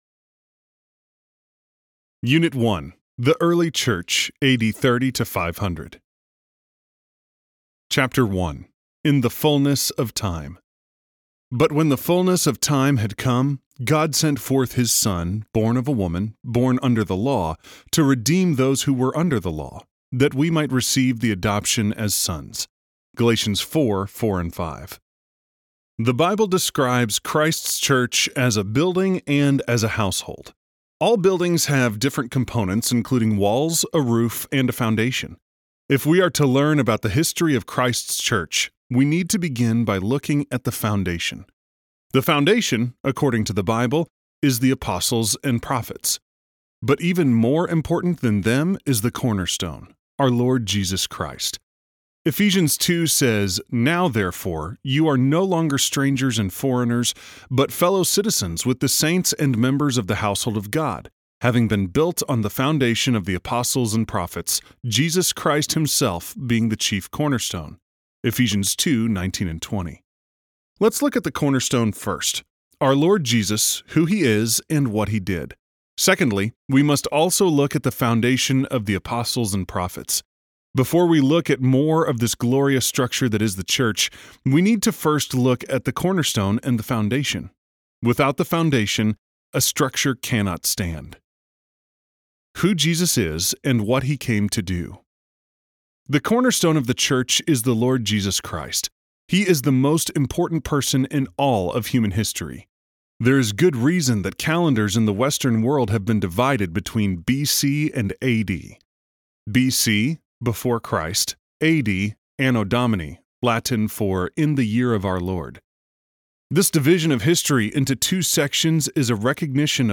Audiobook Download, 14 hours 48 minutes